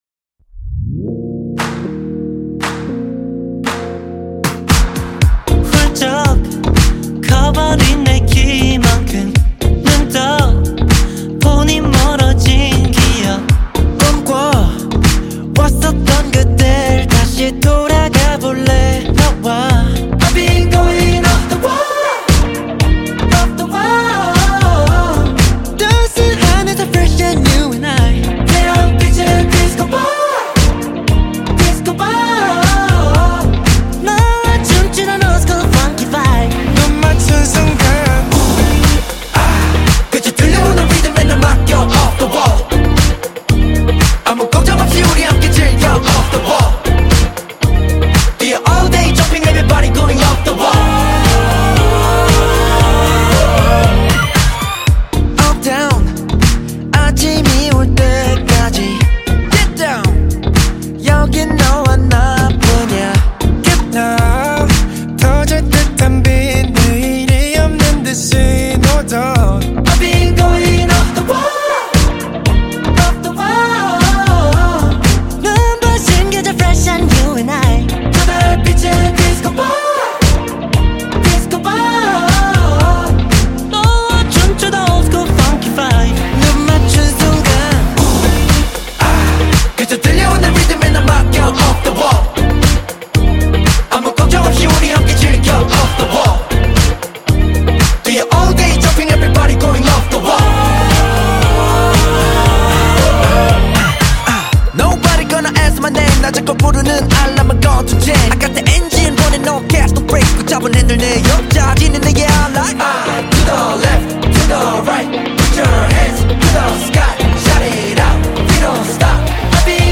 KPop Song